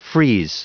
Prononciation du mot frieze en anglais (fichier audio)
Prononciation du mot : frieze